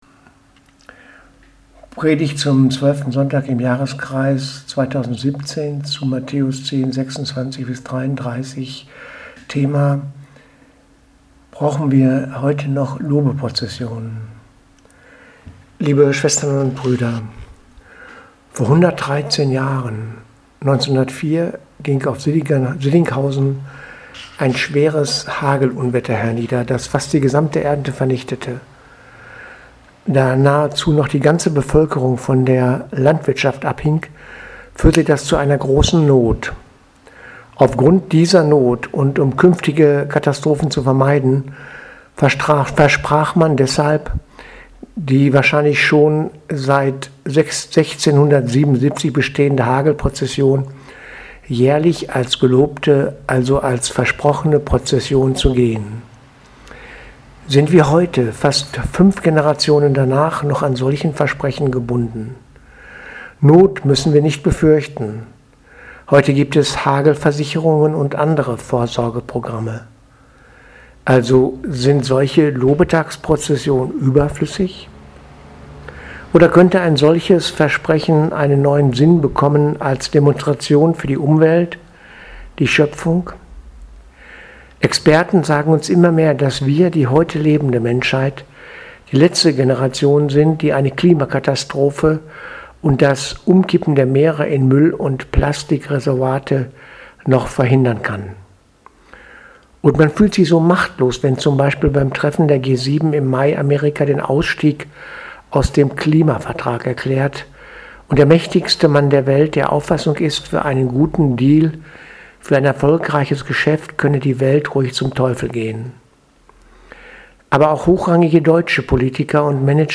Predigt vom 25.6.2017 – Gelobte Prozession